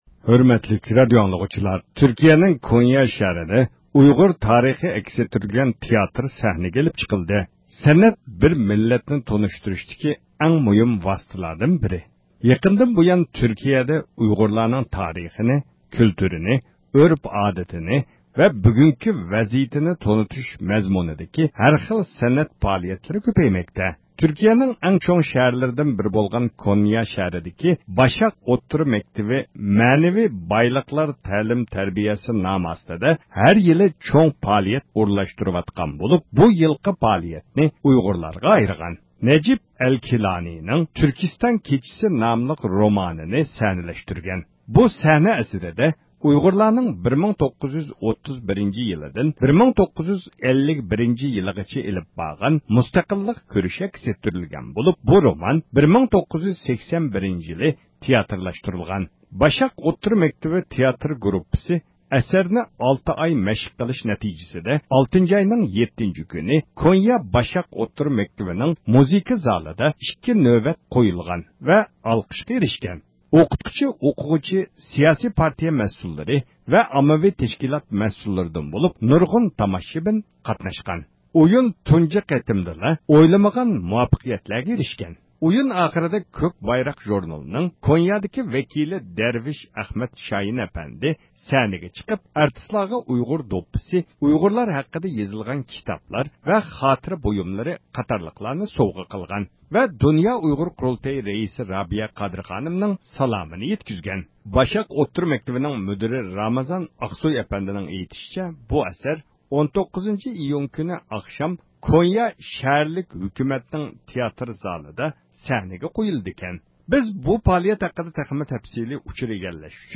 ئىختىيارى مۇخبىرىمىز